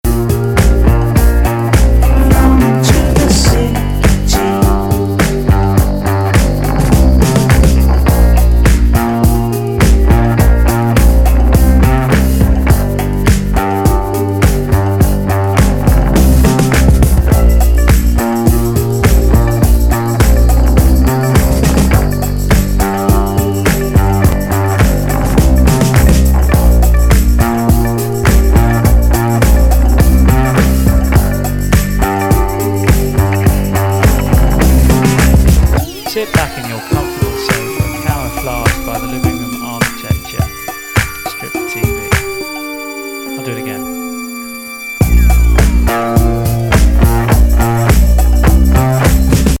ロンドンをベースにしたデュオ
脱力感満点のストレンジ・ポップ!ダビーなミッド・テンポ・トラックがビルドアップ